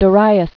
(də-rīəs) Known as "Darius the Great." 550?-486 BC.